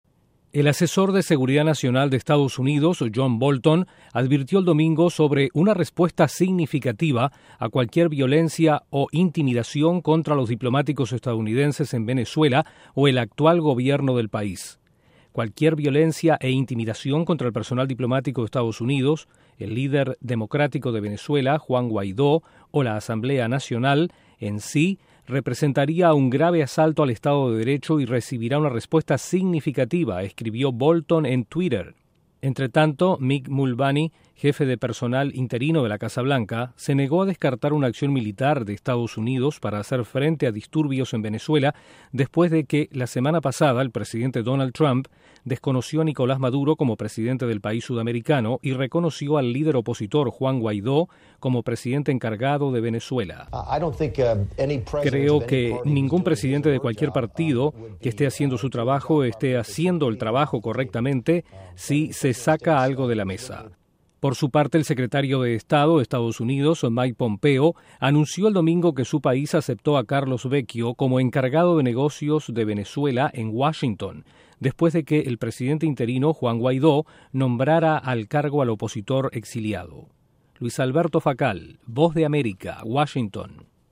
El asesor de seguridad nacional de la Casa Blanca John Bolton advierte que EE.UU. actuaría si su personal diplomático enfrenta violencia en Venezuela. Desde la Voz de América en Washington informa